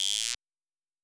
S 78_Guiro2.wav